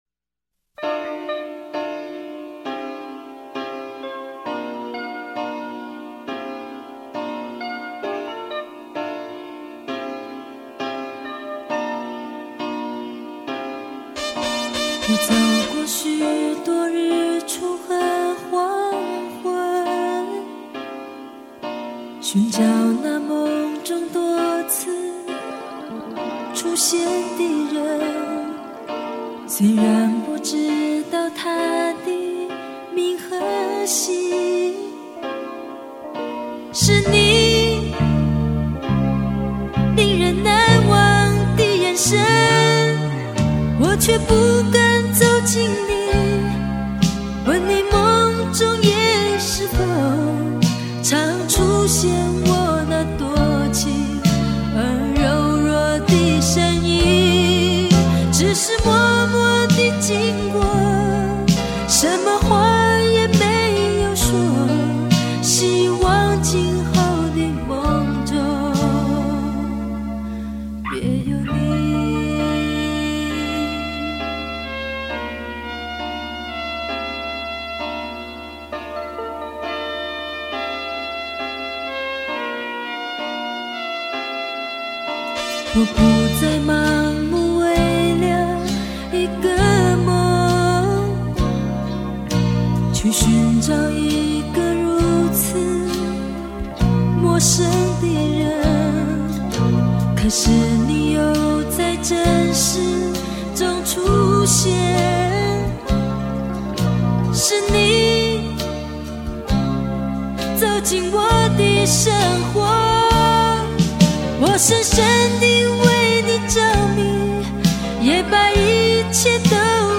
整张专辑都是当时流行英文金曲的重新填词翻唱，由于歌曲大多没在个人专辑收录，还是比较有价值。